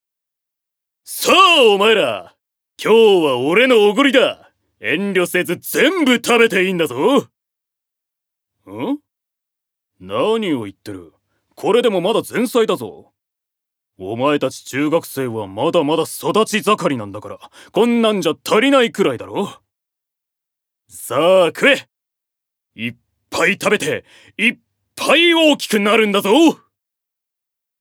Voice Sample
ボイスサンプル
セリフ３